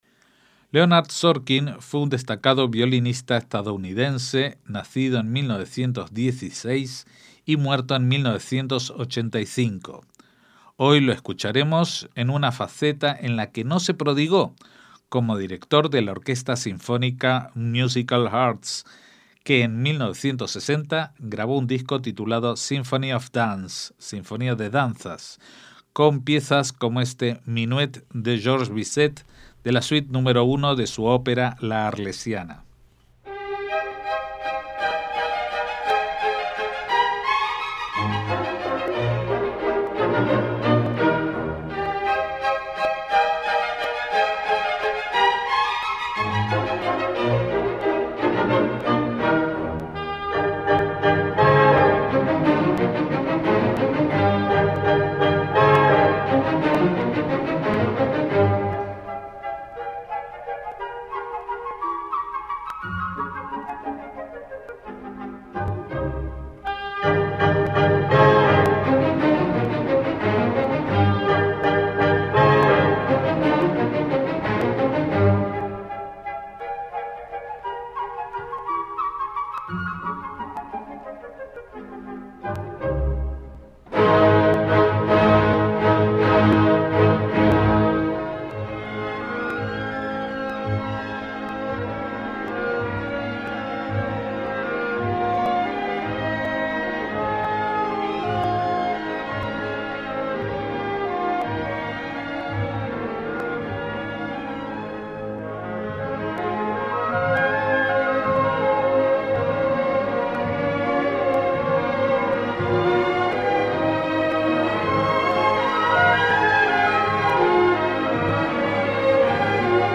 MÚSICA CLÁSICA
en una grabación de clásicos populares de la danza en 1960